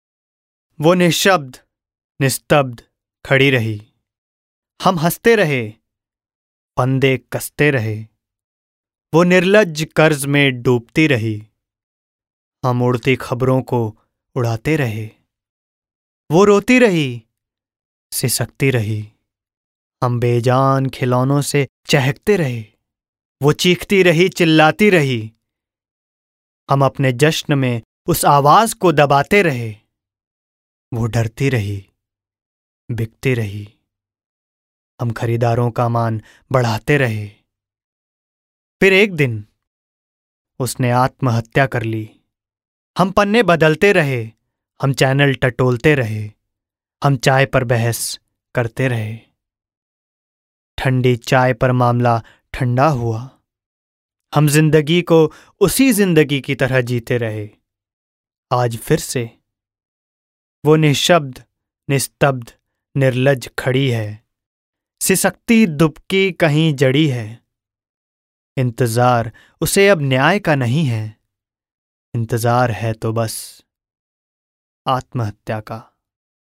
Young, middle aged voice with great command over diction in Hindi, English (Indian) and Marwari (Rajasthani).
Sprechprobe: Werbung (Muttersprache):